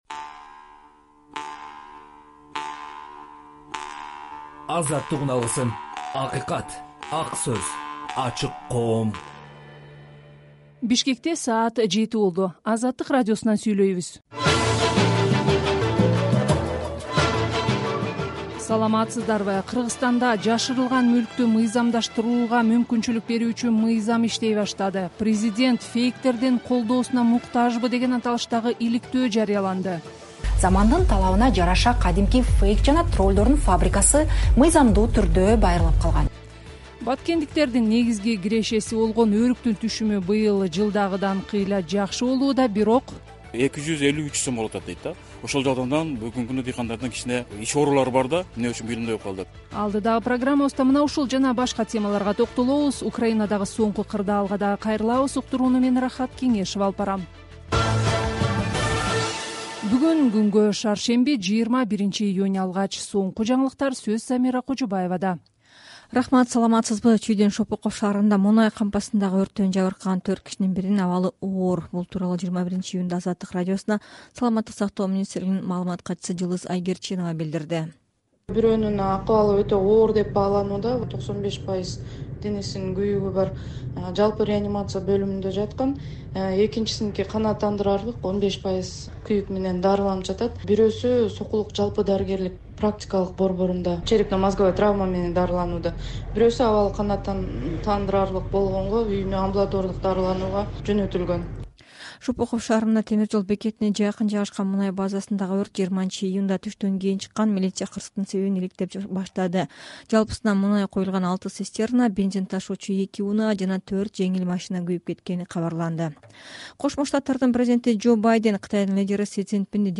Кечки радио эфир | 21.06.2023 | Кыргызстандагы “Тролль фабрика” жөнүндө иликтөө чыкты